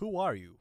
Voice Lines / Dismissive
who are you.wav